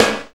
NJS SNR 19.wav